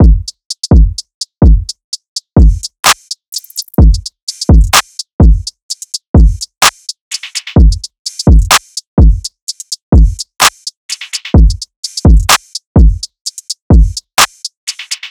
Just Add Flute Drums.wav